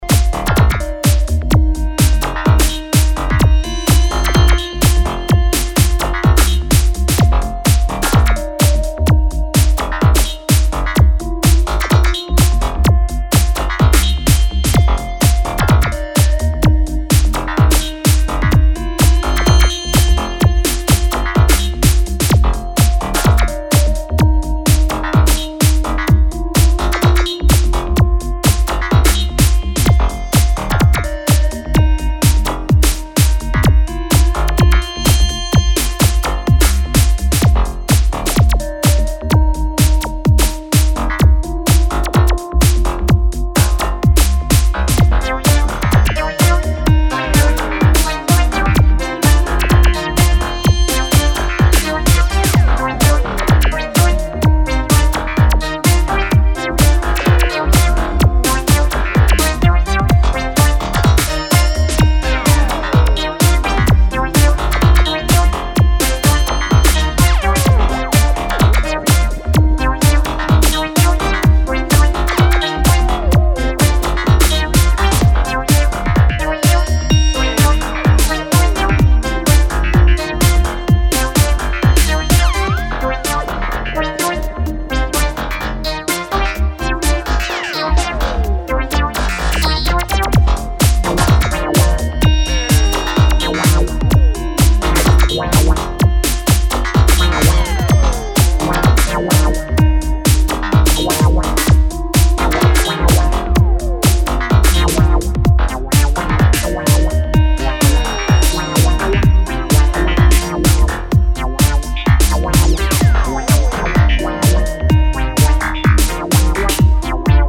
electro, italo-disco and acid infected floor burners!